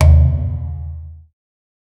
Authentic Wankel rotary engine exhaust pop, short sharp burst with metallic resonance, clean and noise-free, dry professional studio recording.
authentic-wankel-rotary-e-6rasakwh.wav